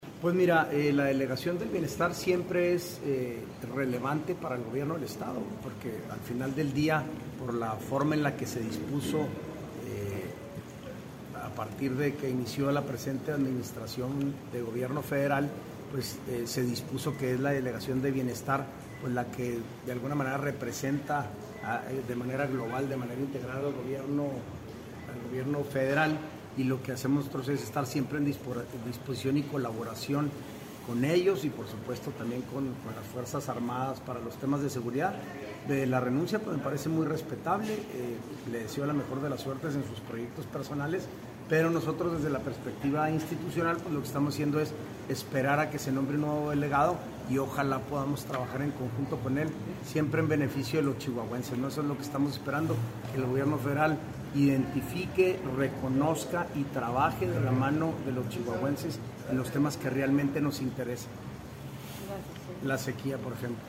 AUDIO: SANTIAGO DE LA PAÑA GRAJEDA, TITULAR DE LA SECRETARÍA GENERAL DE GOBIERNO (SGG)